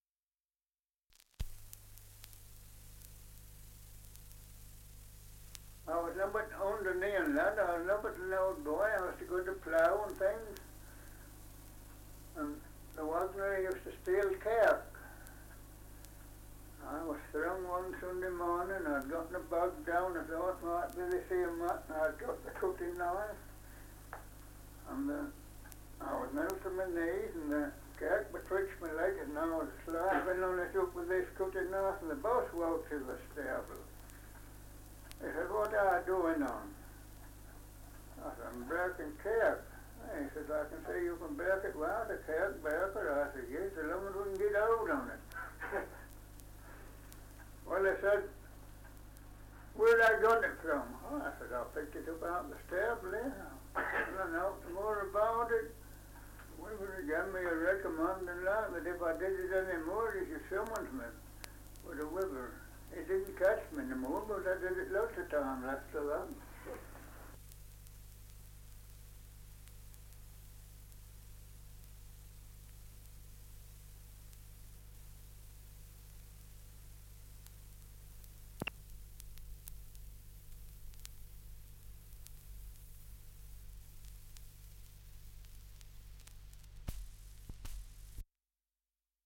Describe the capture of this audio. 2 - Survey of English Dialects recording in Tealby, Lincolnshire 78 r.p.m., cellulose nitrate on aluminium